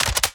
Added more sound effects.
GUNMech_Insert Clip_05_SFRMS_SCIWPNS.wav